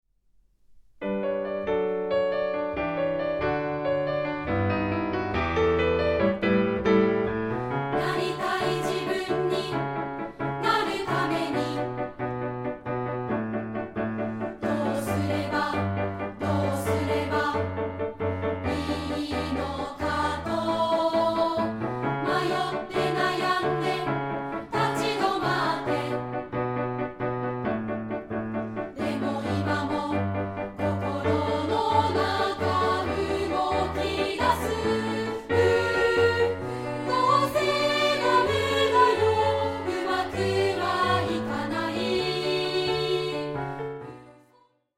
範唱＋カラピアノCD付き
2部合唱／伴奏：ピアノ